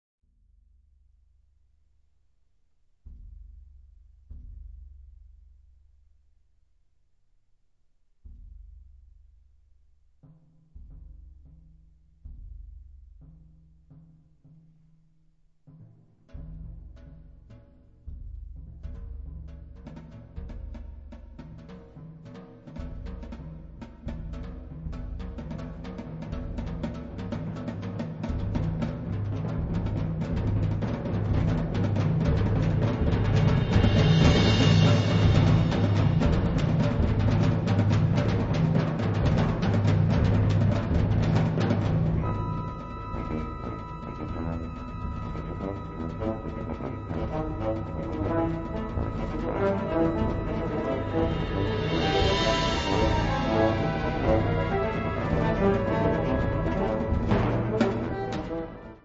Kategorie Blasorchester/HaFaBra
Unterkategorie Zeitgenössische Musik (1945-heute)
Besetzung Ha (Blasorchester)